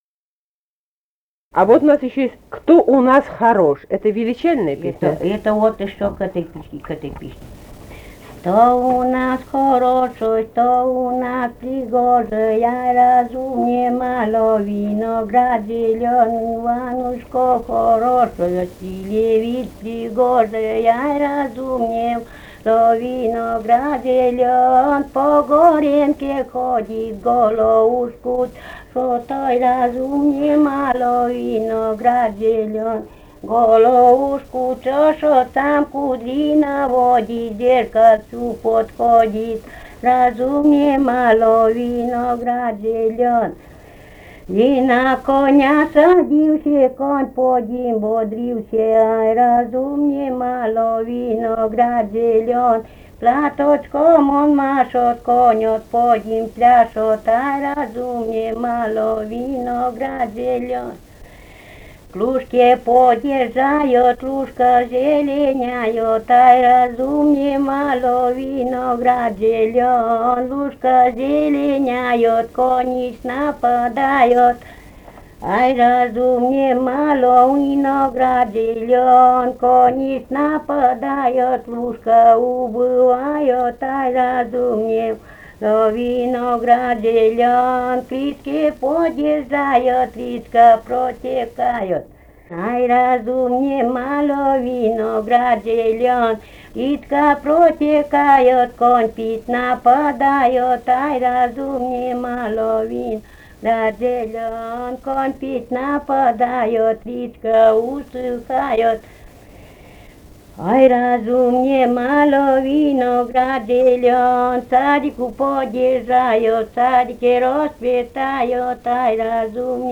«Кто у нас хорошой» (свадебная).